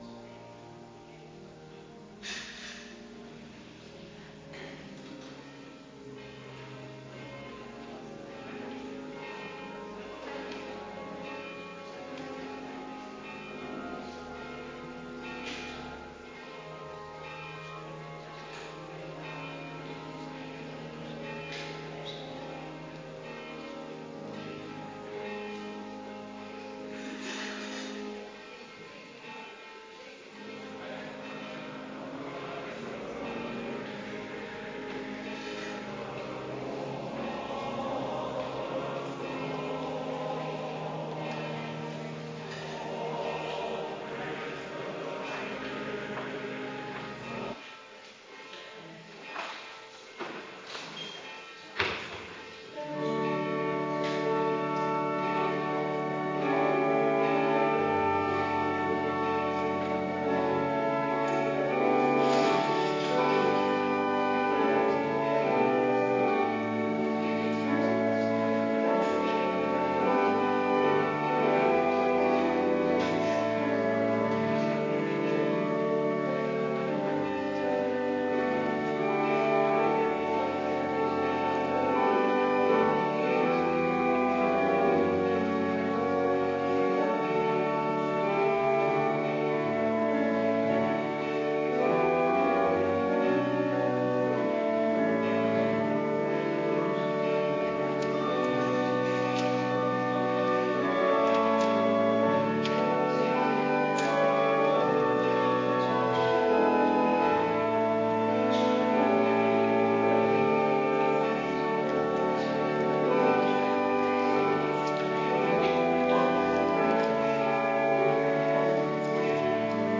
Kerkdiensten
Adventkerk Zondag week 39